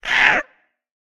Minecraft Version Minecraft Version snapshot Latest Release | Latest Snapshot snapshot / assets / minecraft / sounds / mob / ghastling / hurt4.ogg Compare With Compare With Latest Release | Latest Snapshot
hurt4.ogg